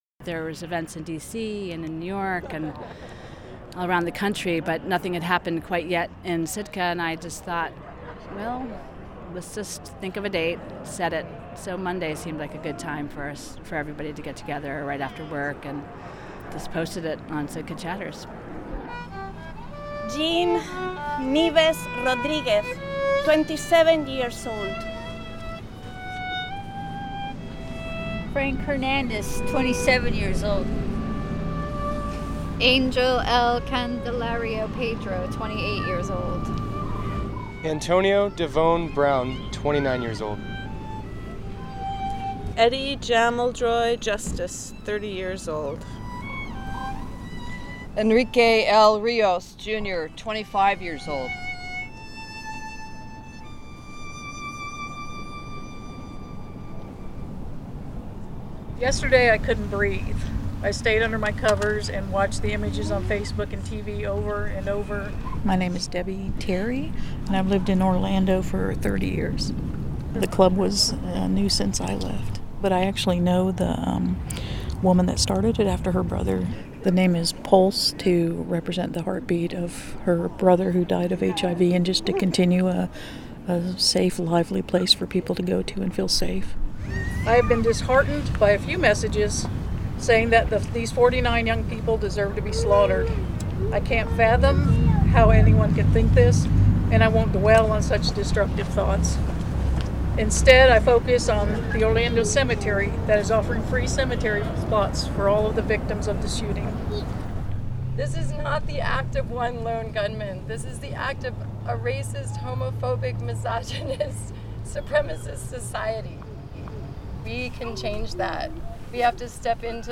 Sitkans read the names of the 49 victims of the Orlando shootings, which range in age from 18 to 50.